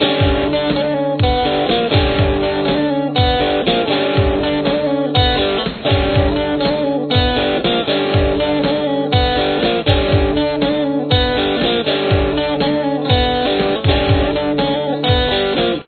Guitar 2